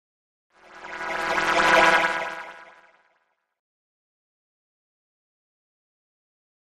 Sci Fi Pass By Electronic Buzz Wash with Phase